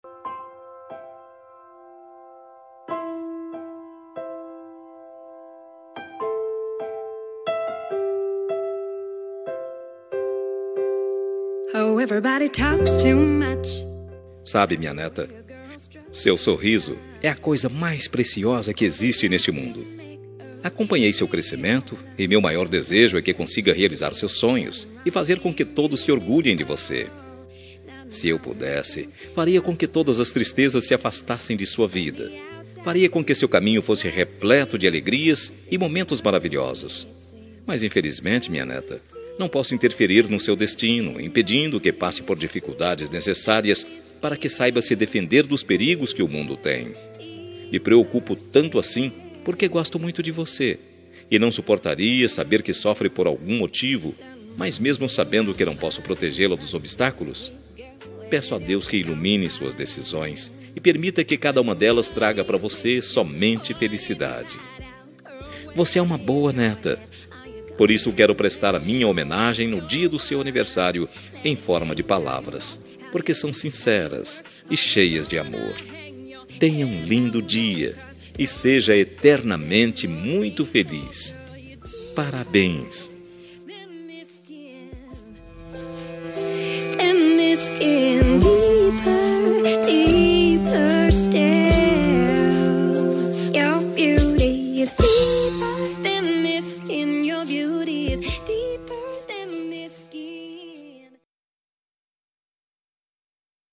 Aniversário de Neta – Voz Feminina – Cód: 131030